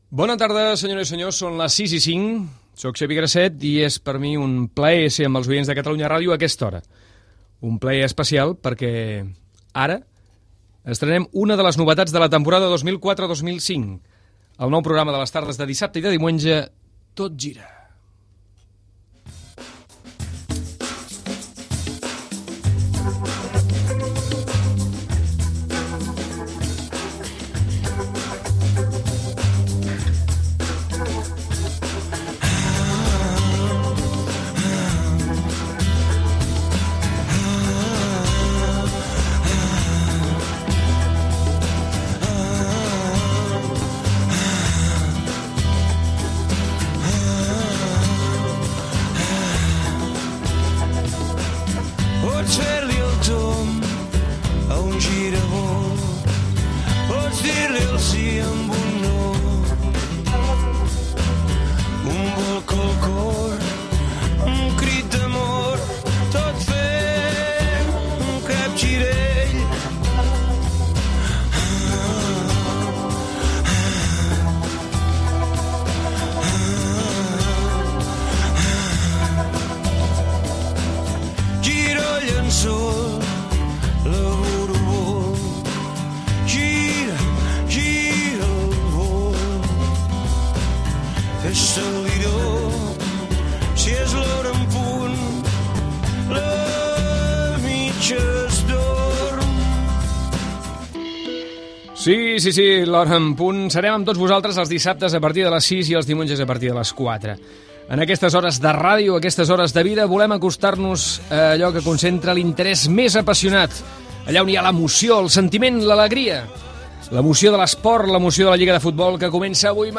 Inici del primer programa, tema musical, continguts que s'oferiran, indicatiu del programa, diàleg sobre l'inici de la lliga de futbol masculí i principals partits de la jornada, connexió amb el Mini Estadi del Futbol Club Barcelona, comentari de la jornada
Esportiu
FM